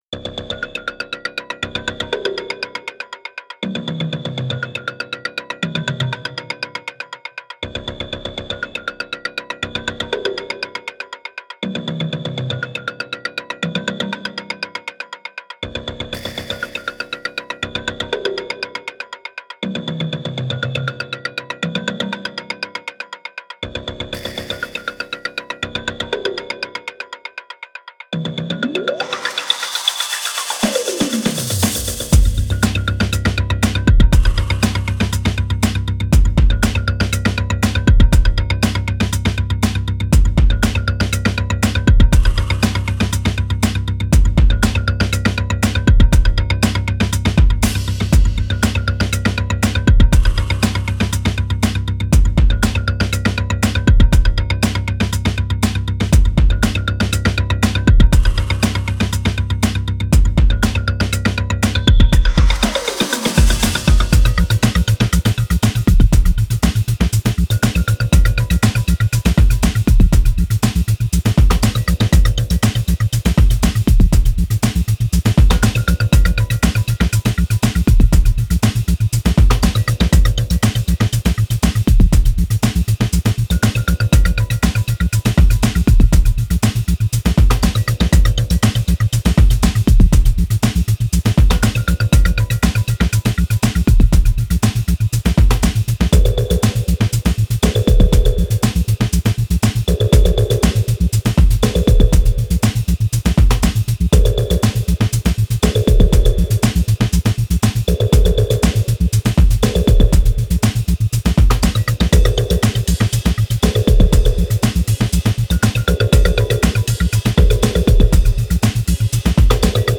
• Жанр: Electronic